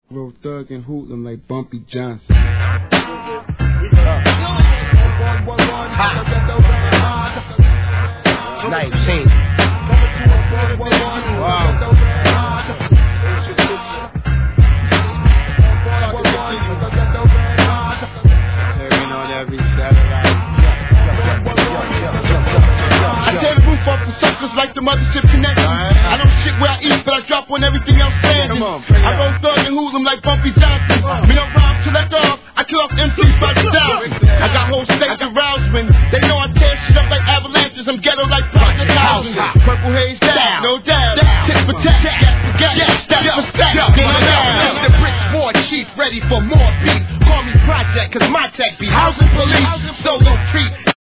HIP HOP/R&B
単調なBEATにキレキレのRAPかっけ〜す!